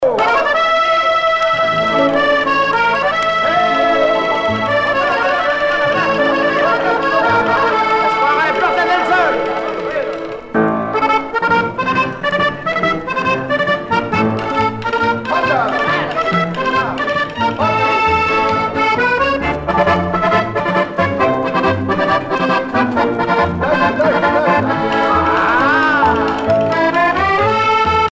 danse : paso musette
Pièce musicale éditée